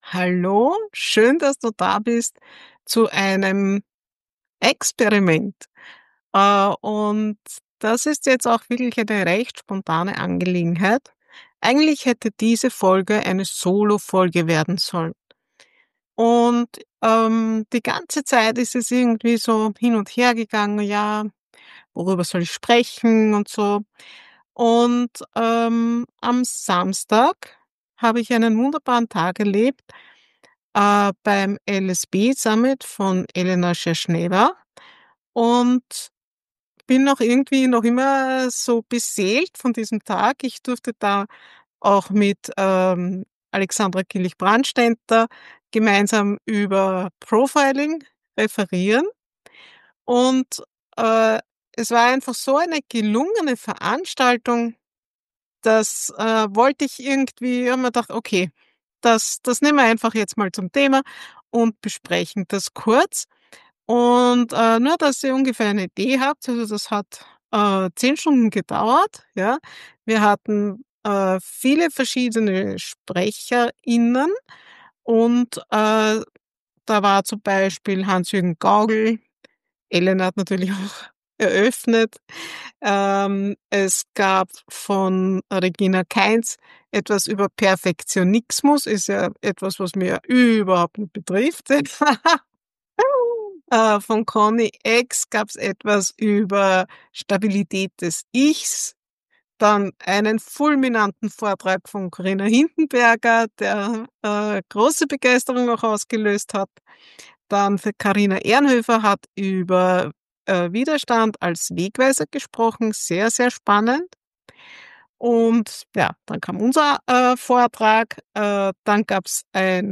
Du erfährst, wie Loyalität, innere Konflikte und Perfektionismus dazu beitragen können, viel zu lange in ungesunden Strukturen zu bleiben, warum sie sich für eine Karenz bzw. später den endgültigen Ausstieg entschieden hat, wie sie mit Hilfe von Beratung und Unternehmensgründungsprogramm den Weg in die Selbstständigkeit gefunden hat, und wie sie heute als psychosoziale Beraterin, Supervisorin und Unternehmensberaterin traumasensibel mit Berater*innen arbeitet, die ihre eigenen Muster besser verstehen und ein „schamlos authentisches“ Business aufbauen wollen. Die Folge verbindet Event-Atmosphäre, echte Berufsbiografie, innere Entwicklungsprozesse und Mut zur Neuorientierung – genau das Richtige für dich, wenn du spürst: So wie bisher soll es nicht bleiben, aber ich weiß noch nicht genau, wie der nächste Schritt aussieht.